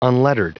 Prononciation du mot unlettered en anglais (fichier audio)
Prononciation du mot : unlettered